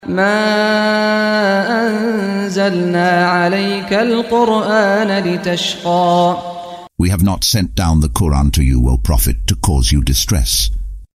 Audio version of Surah Taha in English, split into verses, preceded by the recitation of the reciter: Saad Al-Ghamdi.